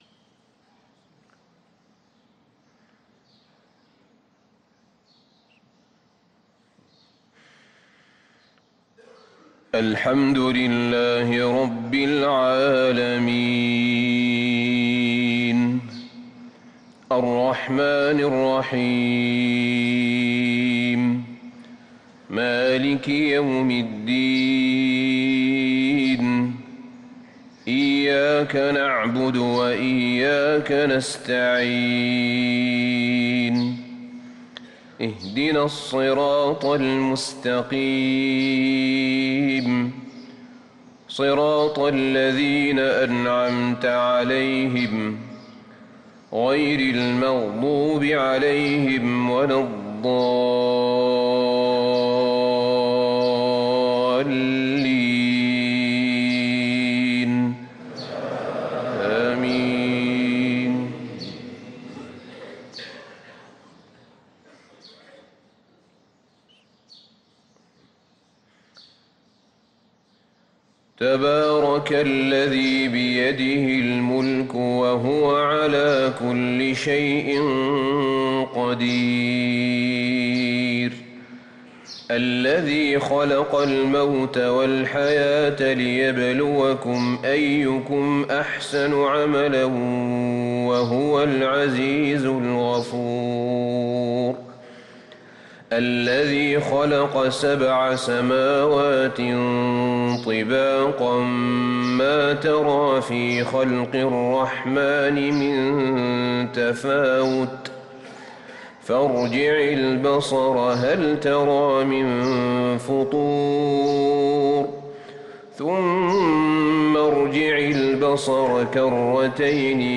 صلاة الفجر للقارئ أحمد بن طالب حميد 1 جمادي الأول 1445 هـ
تِلَاوَات الْحَرَمَيْن .